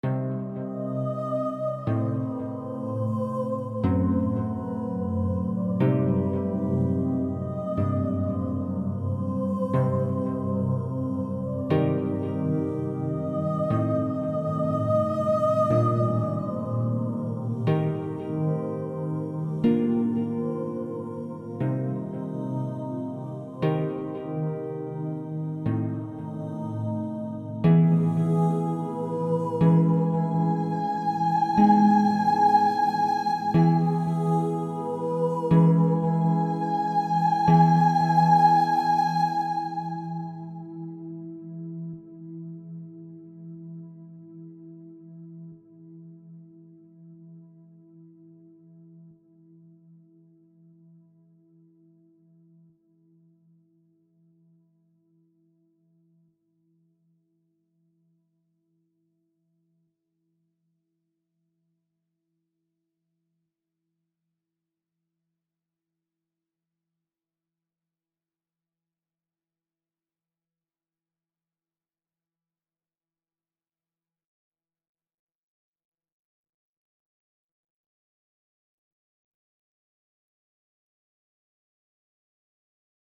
The same Banshee songs as a multi part arrangement